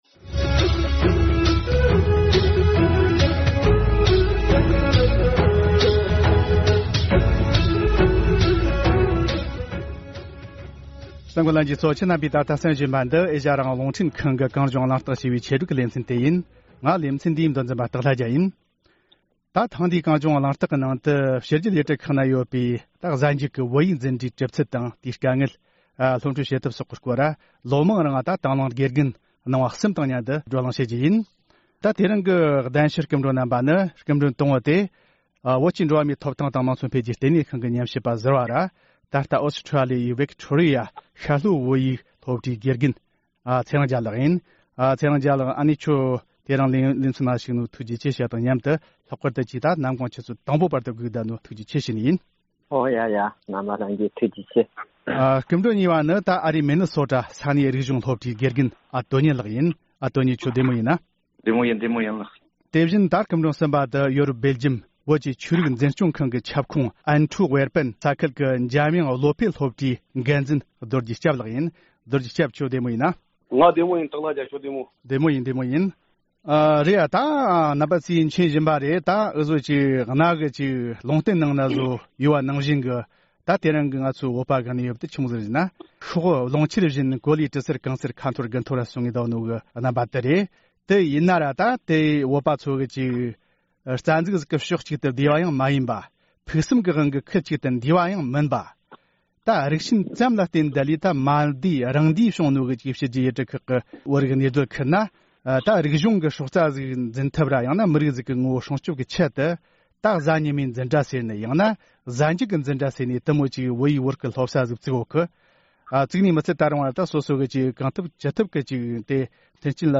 ༄༅༎ ཐེངས་འདིའི་གངས་ལྗོངས་གླེང་སྟེགས་ཀྱི་ནང་དུ། ཕྱི་རྒྱལ་ཡུལ་གྲུ་ཁག་ན་ཡོད་པའི་གཟའ་ཉི་མའི་འཛིན་གྲྭ་ཡང་ན་གཟའ་མཇུག་གི་འཛིན་གྲྭའི་གྲུབ་ཚུལ་དང་དཀའ་ངལ། སློབ་ཁྲིད་བྱེད་ཐབས་སོགས་ཀྱི་སྐོར་ལོ་མང་རིང་དང་བླངས་དགེ་རྒན་གནང་བ་གསུམ་དང་མཉམ་དུ་བགྲོ་གླེང་གནང་རྒྱུ་ཡིན།